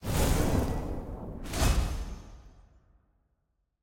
sfx_ui_combat_minordefeat.ogg